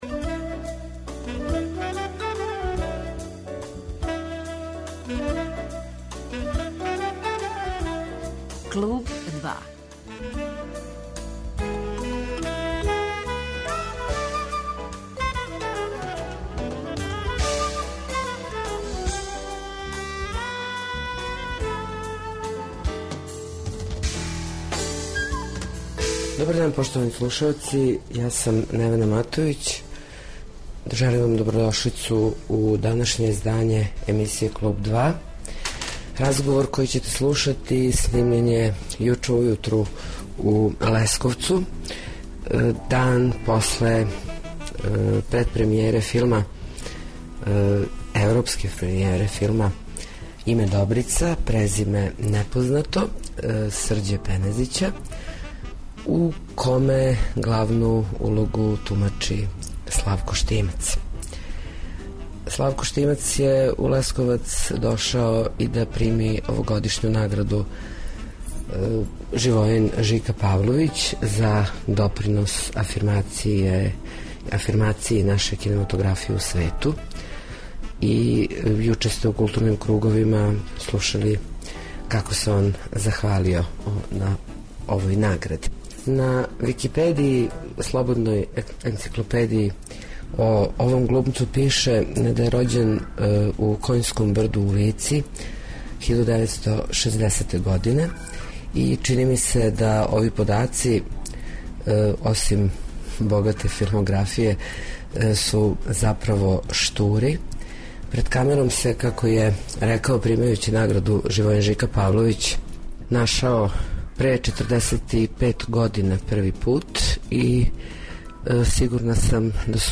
Гост 'Клуба 2' је глумац Славко Штимац.
Данашње, специјално издање емисије снимљено је у Лесковцу, где је у току девето издање Фестивала филмске режије. 9.